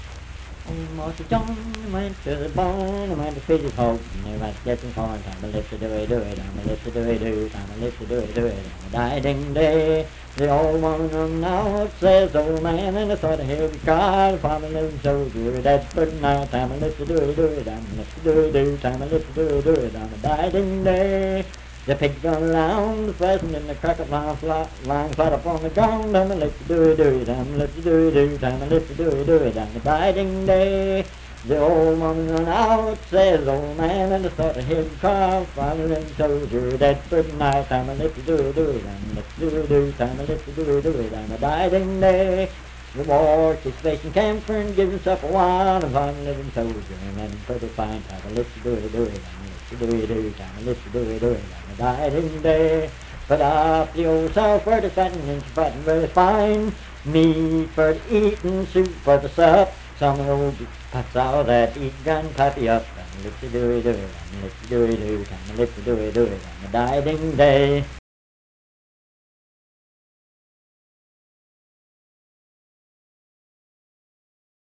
Unaccompanied vocal music
Verse-refrain 6(4w/R). Performed in Dundon, Clay County, WV.
Voice (sung)